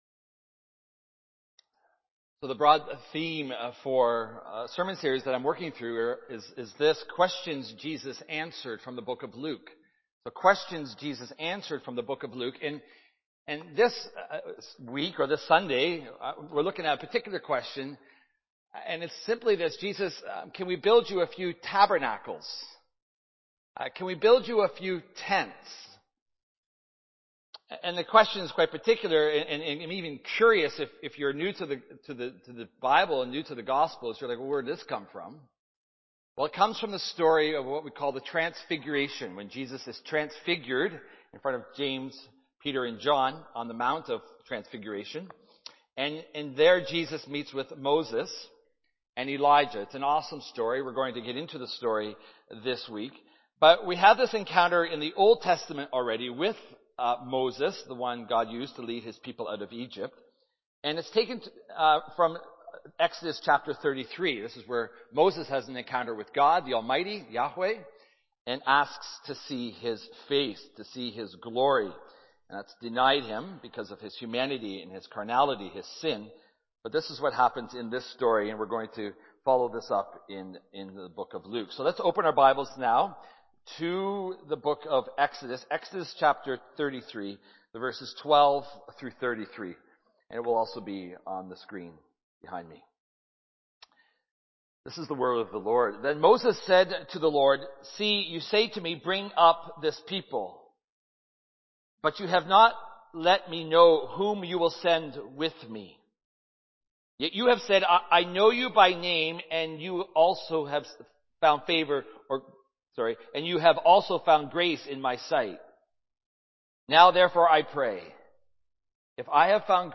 Transfiguration Series Non-Series Sermon Book Luke Watch Listen Read Save Sorry